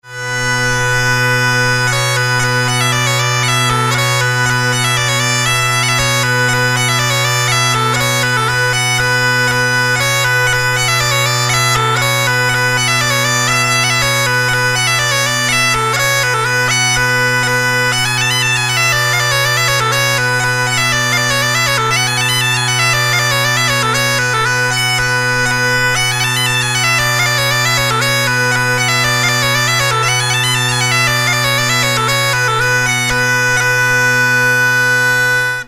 Mittelalter Dudelsack in H-moll:
Er besitzt genau wie mein großer Mittelalterdudelsack in G/a ein Holzrohrblatt in der Spielpfeife, wodurch er diesem in Lautstärke und Klangfülle ebenbürtig ist.
Tonumfang Spielpfeife: a'- h''
Spielbare Tonarten: h-dorisch / h-moll
Bordunstimmung:  Bassbordun - H.....
Klangbeispiel
H-moll_Sack.mp3